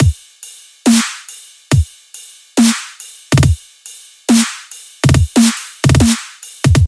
炸弹人风格的鼓
描述：踢腿+小鼓+骑马完美...
标签： 140 bpm Dubstep Loops Drum Loops 1.16 MB wav Key : Unknown
声道立体声